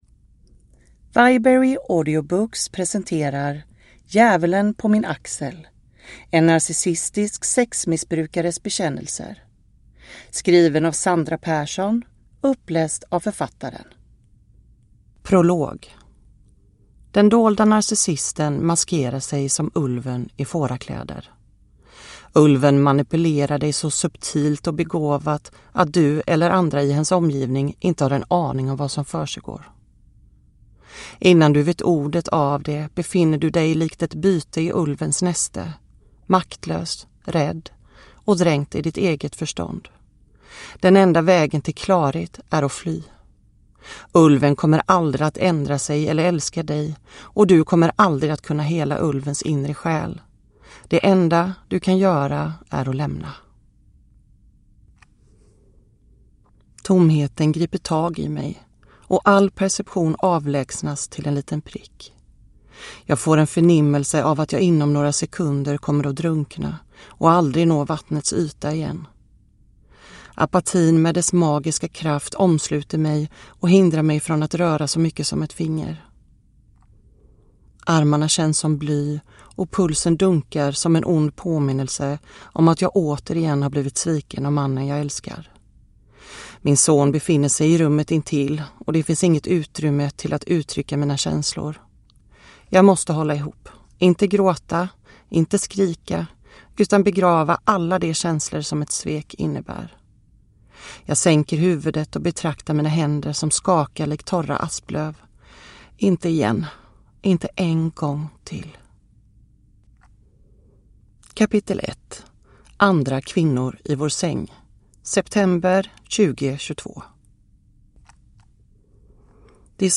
Djävulen på min axel - en narcissistisk sexmissbrukares bekännelser / Ljudbok